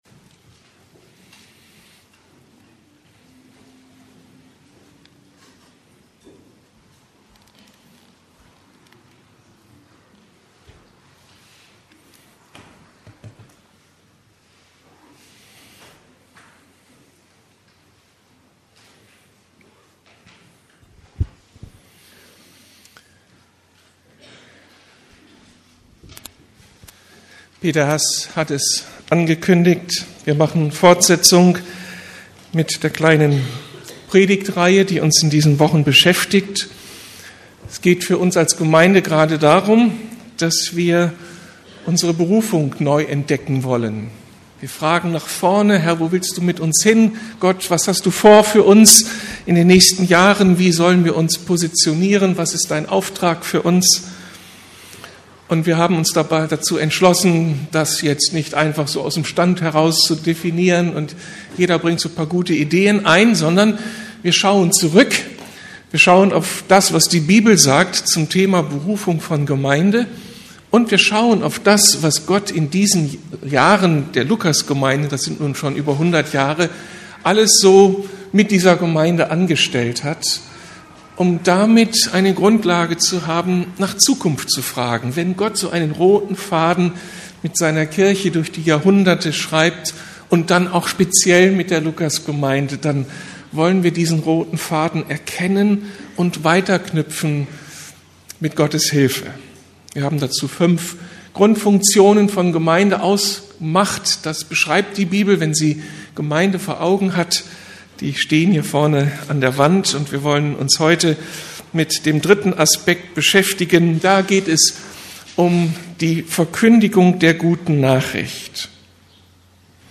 Berufen zur Verkündigung der guten Nachricht ~ Predigten der LUKAS GEMEINDE Podcast